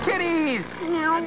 kitties.wav